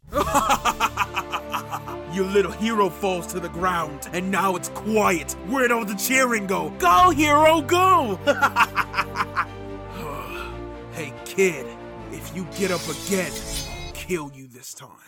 Teen
Young Adult
Character Voice